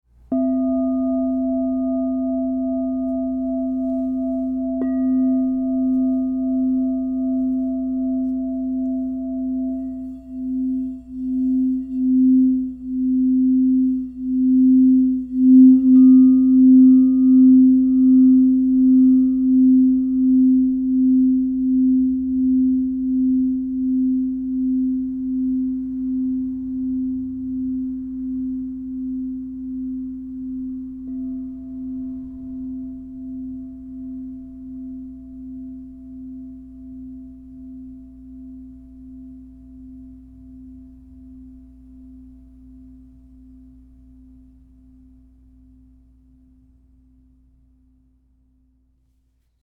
Palladium, Rose Quartz 9" C -20 - Divine Sound